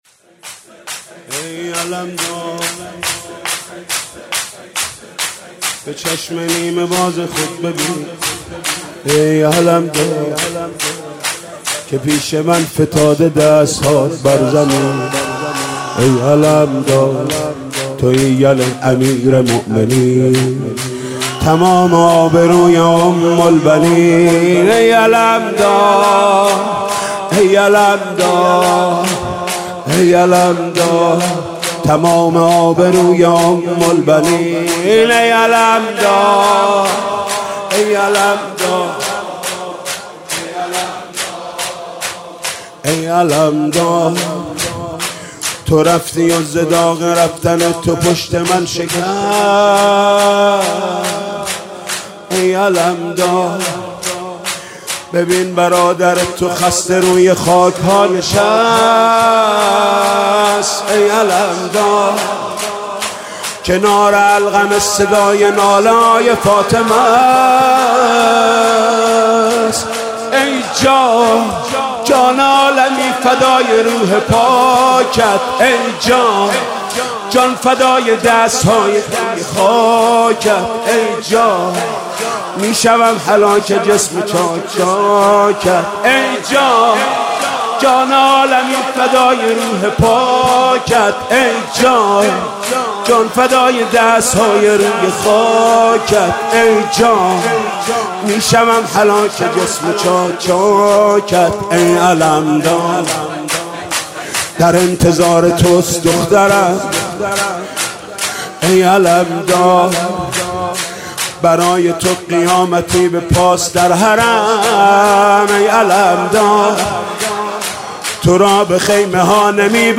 شور: ای علمدار به چشم نیمه باز خود ببین